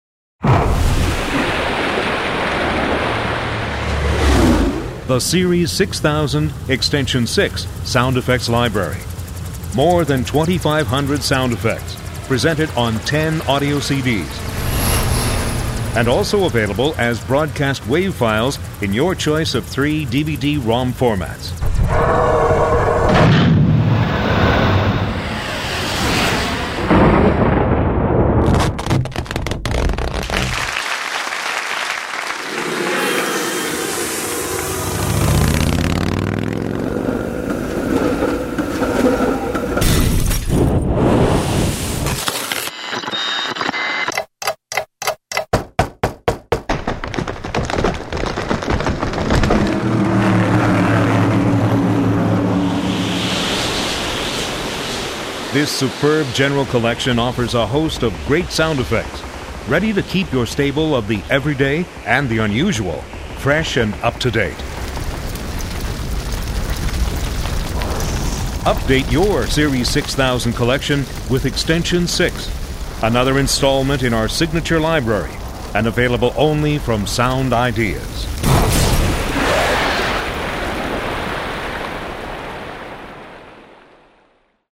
アコーディオン、酸、エアコン、ジェット機、古い飛行機
バリバリいう音、キューバの環境、泥の音、皿、ドア、布、ドラム、衣類乾燥機、電子音、蒸気機関、爆発、ファン、事務所、火事、釣りざお、食物、歩み、フリーザー、溶鉱炉、生ごみ入れ、ガラス音、コショウ挽き、ヘアドライヤー、ヘリコプター、人の音